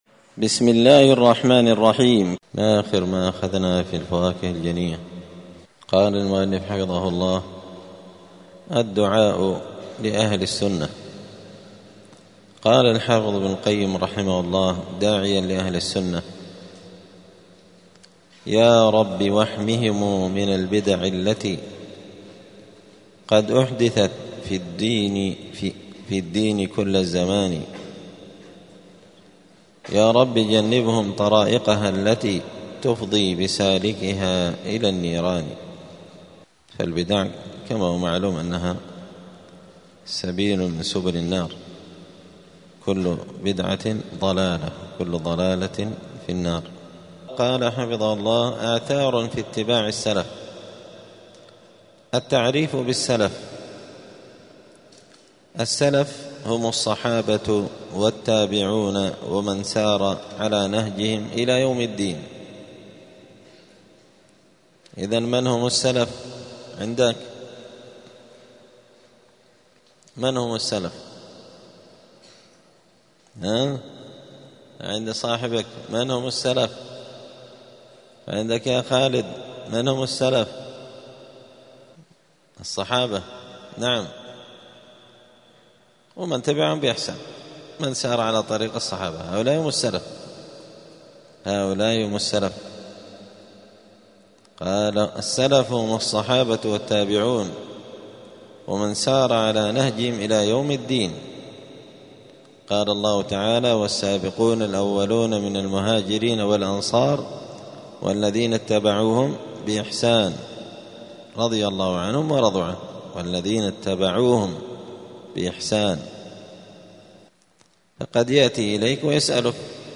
دار الحديث السلفية بمسجد الفرقان بقشن المهرة اليمن
الأحد 28 جمادى الآخرة 1446 هــــ | الدروس، الفواكه الجنية من الآثار السلفية، دروس الآداب | شارك بتعليقك | 99 المشاهدات